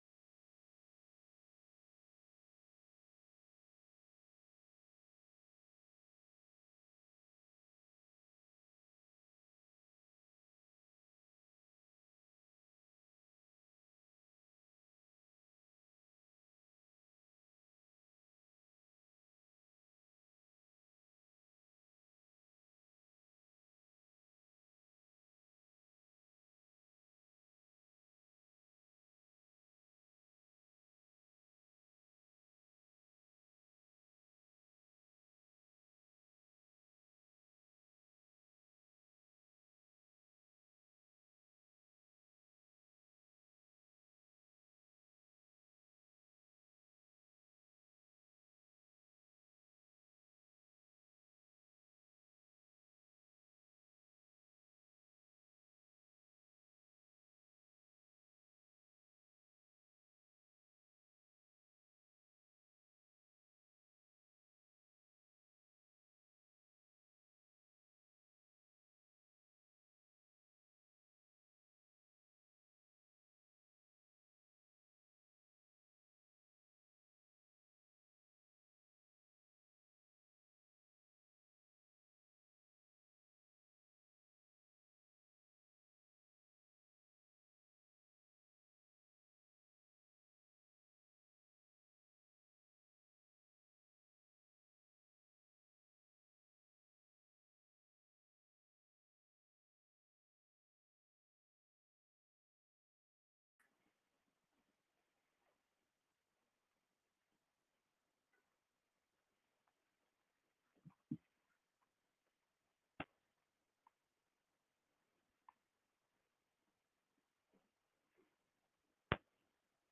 dearborn-open-mic-english-dome-april-2020.mp3